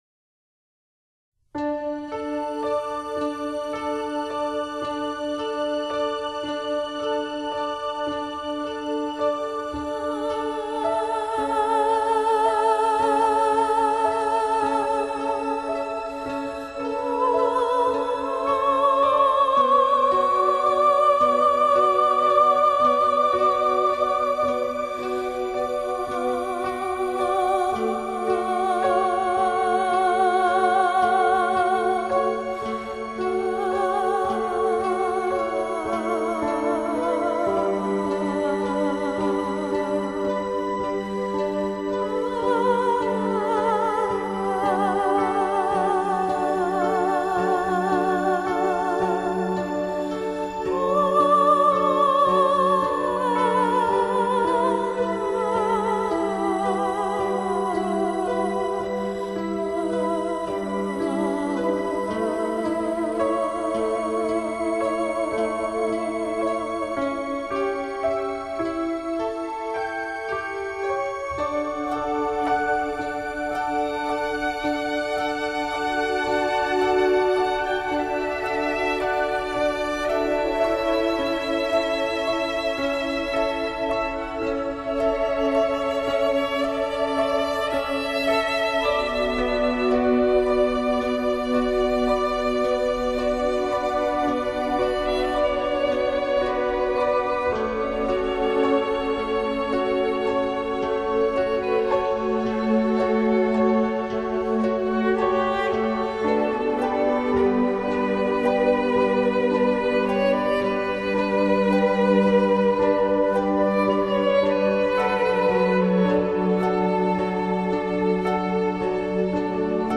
真是感动的乐章，是柔柔的，但柔的那么醉人，是缓缓的，但缓的那么舒心。